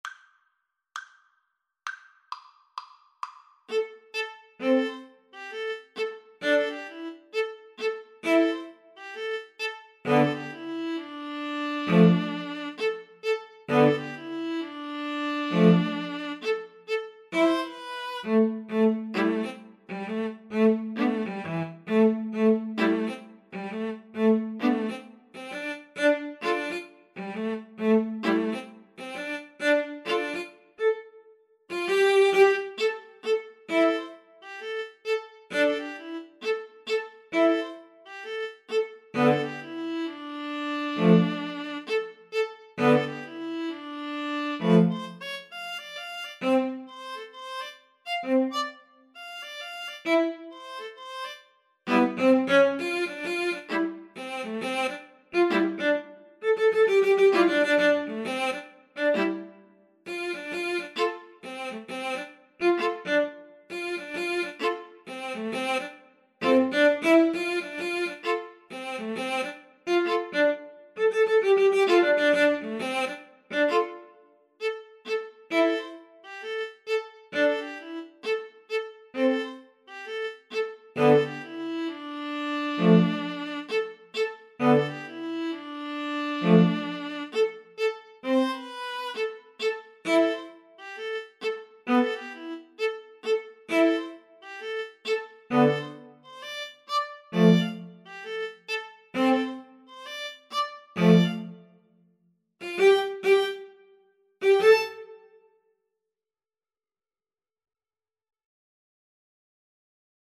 A funky hop-hop style piece.
String trio  (View more Intermediate String trio Music)
Pop (View more Pop String trio Music)